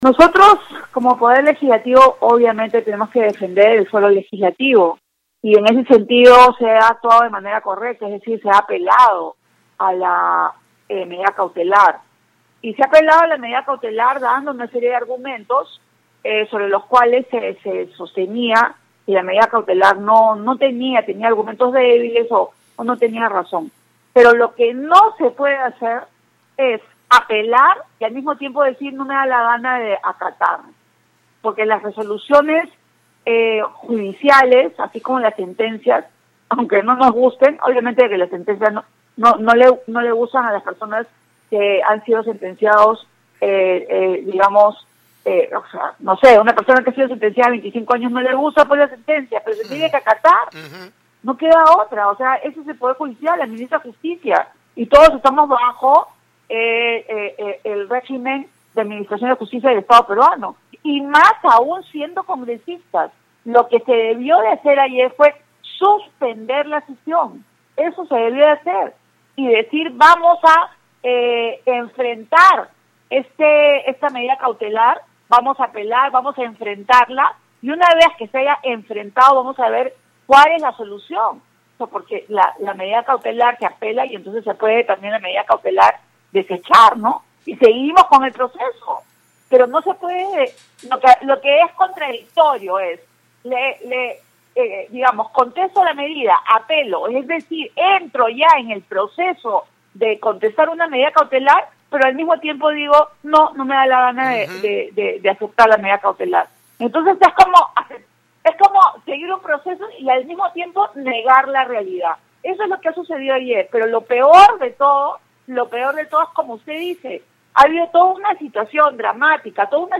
En conversación con Radio Uno, la congresista Rocio Silva-Santisteban, expresó estar en contra de que la junta de portavoces continúe con el proceso de elección de magistrados del Tribunal Constitucional (TC).